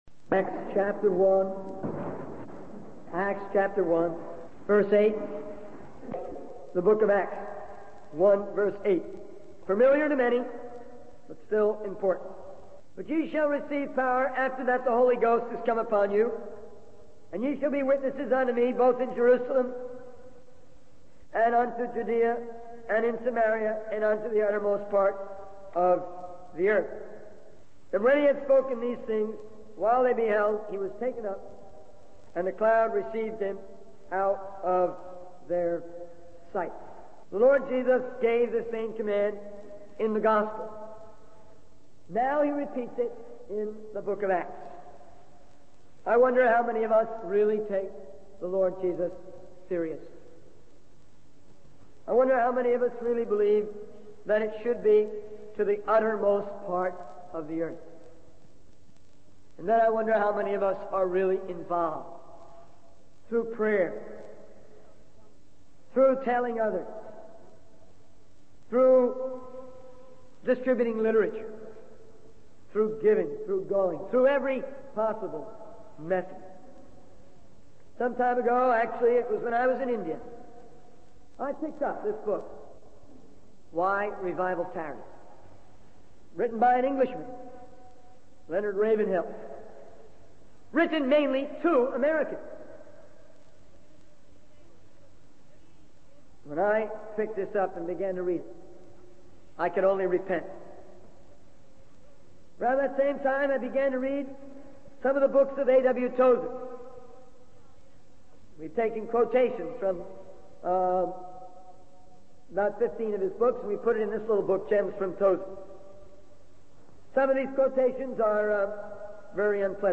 In this sermon, the preacher emphasizes the importance of having a genuine and passionate love for Jesus Christ. He warns against being complacent and distracted by worldly pursuits, urging listeners to prioritize their relationship with God. The preacher calls for a revolution in witnessing and a baptism of humility, highlighting the need for repentance and a humble heart.